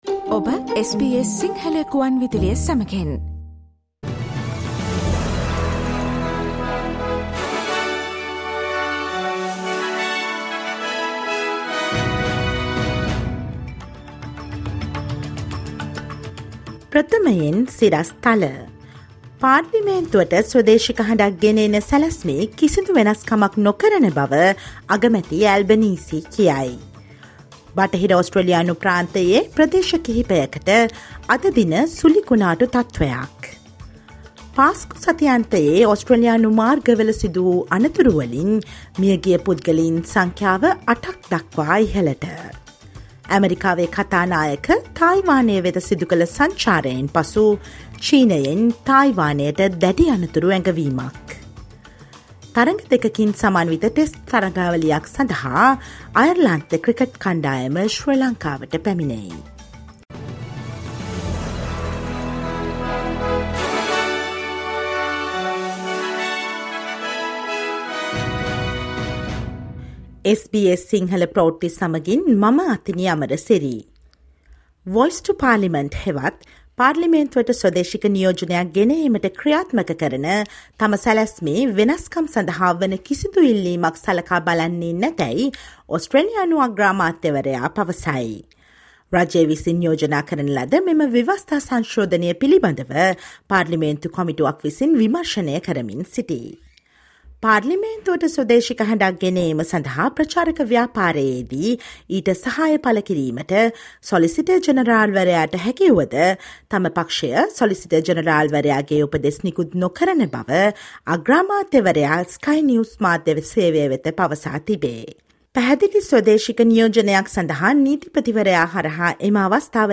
Listen to the latest news from Australia, fro Si Lanka ,across the globe, and the latest sports news on SBS Sinhala radio on Monday, 10 April 2023.